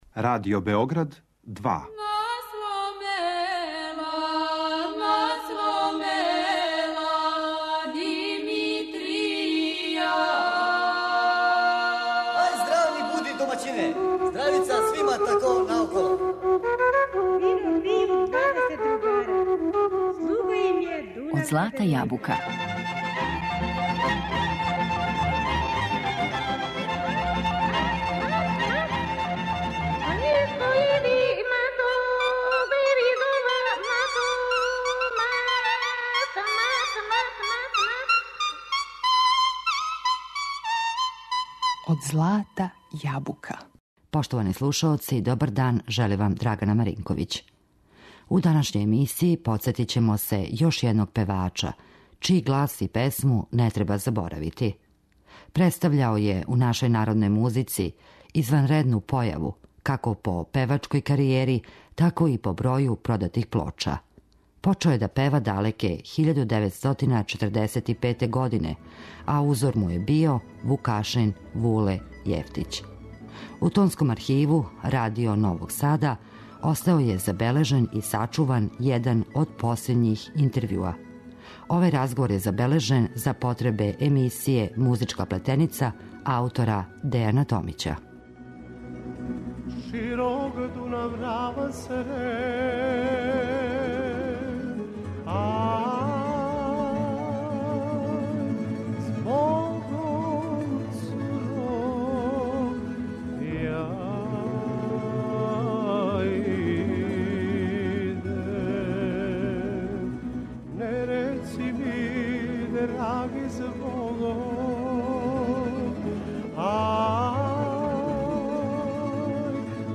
музички портрет